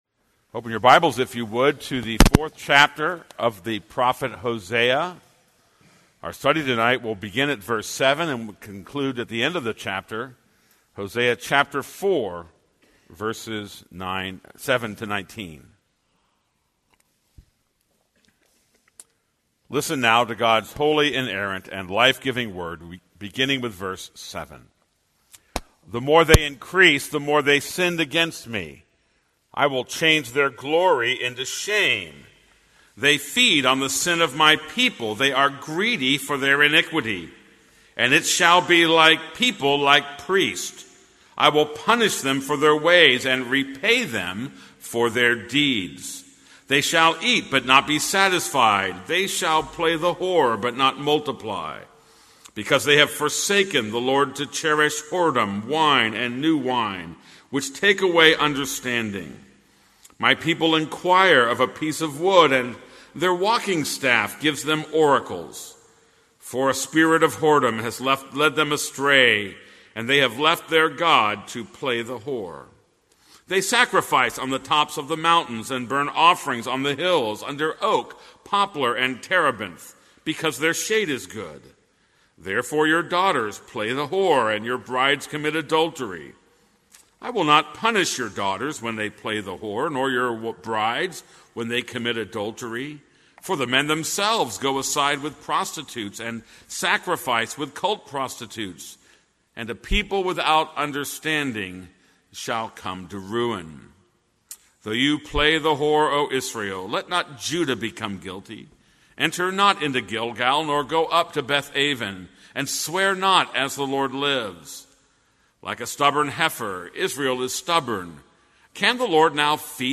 This is a sermon on Hosea 4:7-19.